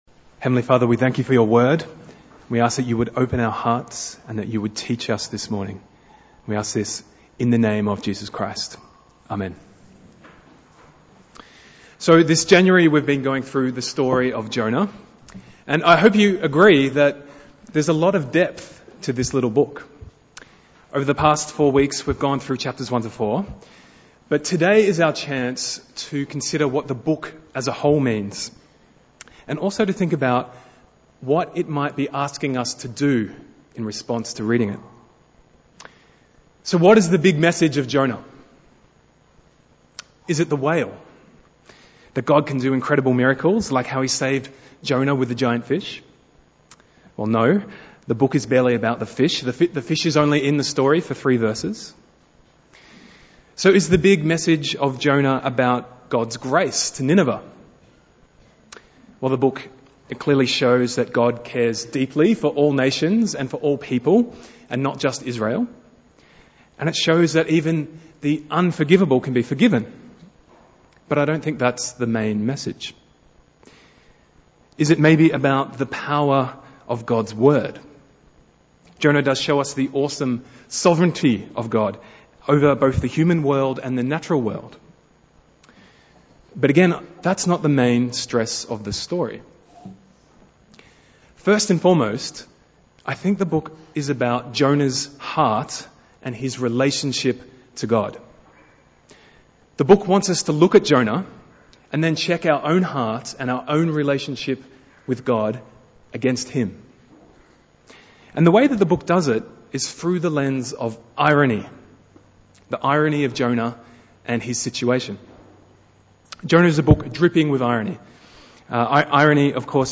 Bible Text: Jonah 1-4 | Preacher